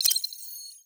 Robotic Game Notification 10.wav